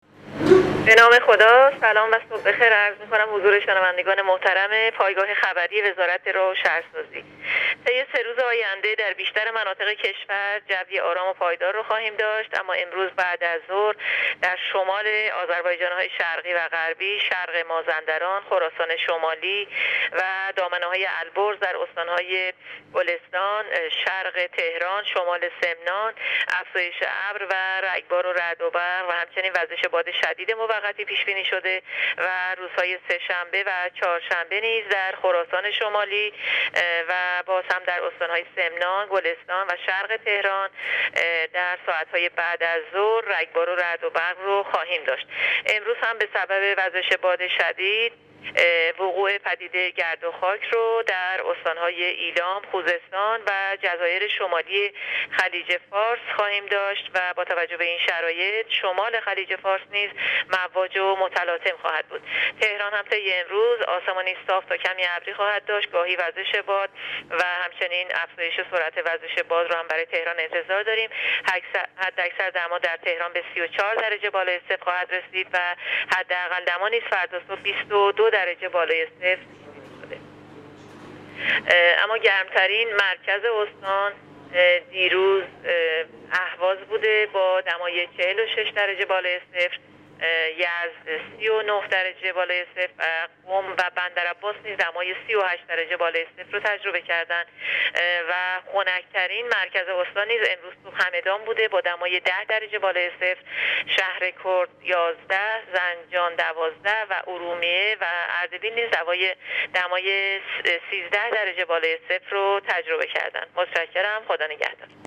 در گفتگو با راديو اينترنتی پايگاه خبری